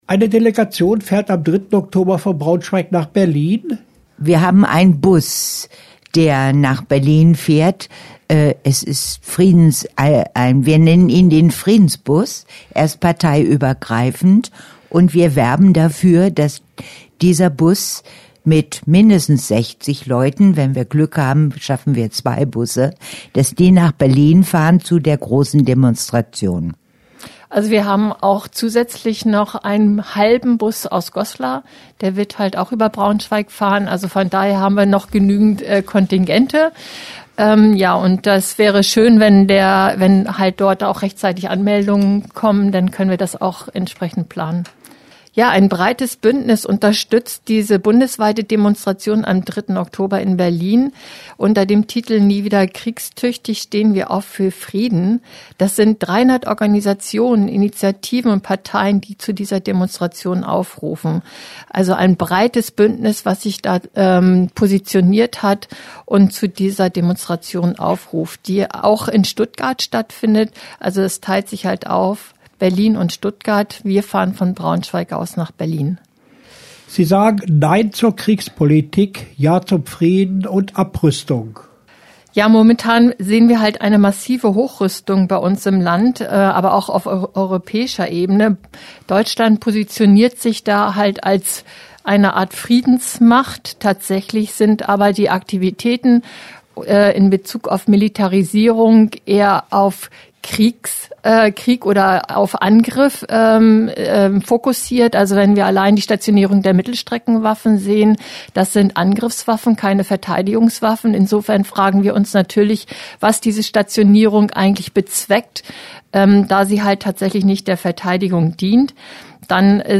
Audiobeitrag für Radio Okerwelle